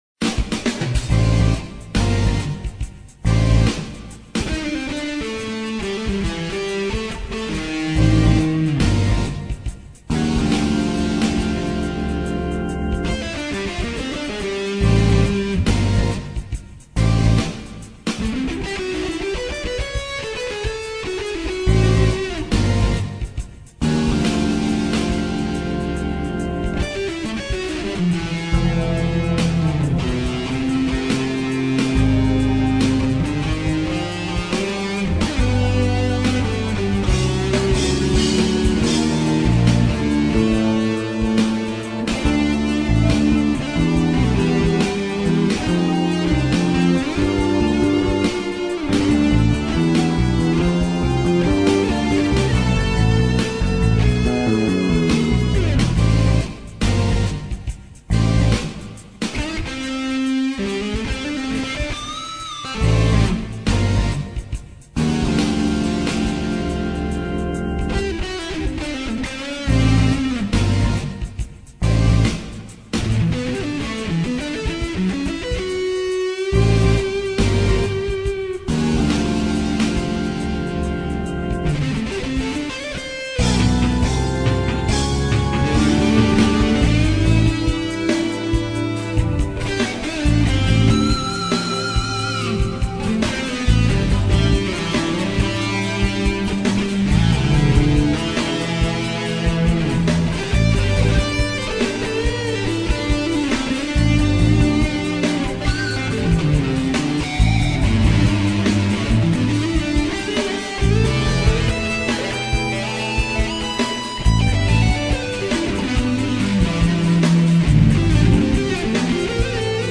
АЛЬТЕРНАТИВНАЯ МУЗЫКА